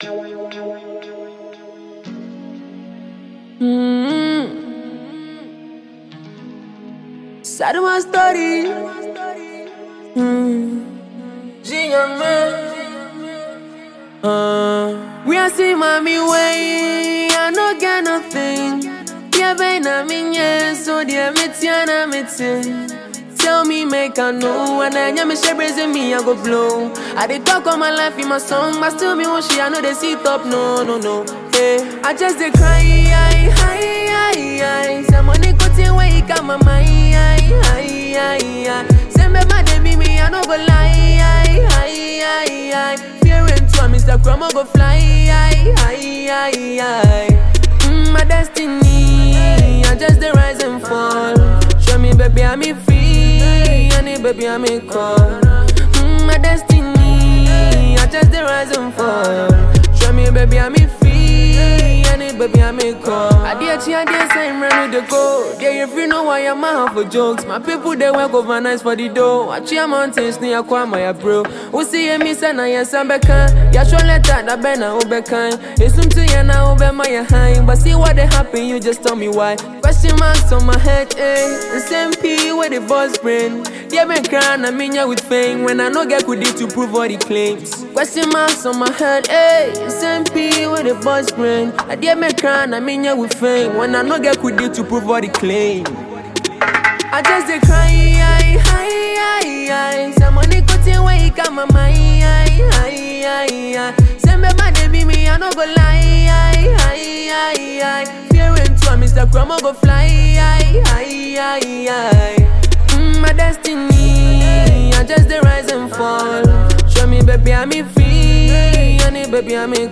a fast rising Ghanaian singer
with his sweet melody voice